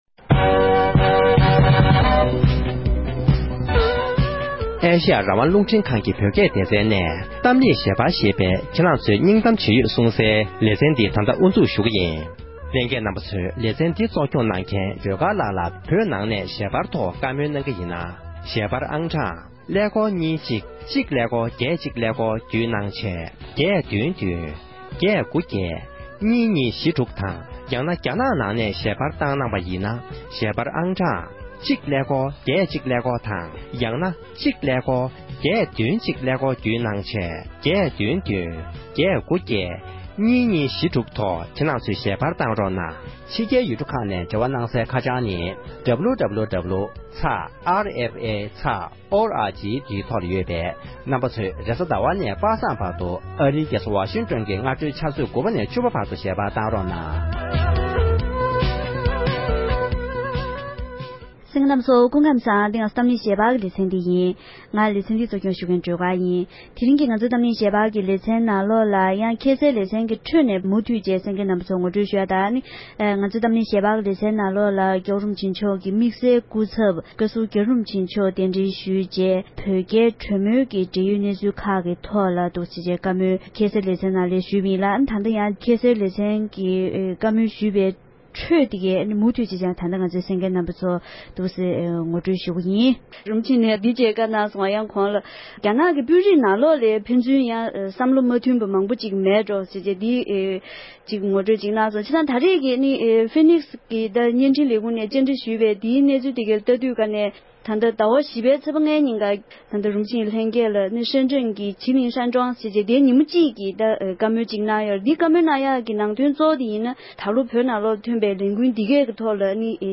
བགྲོ་གླེང་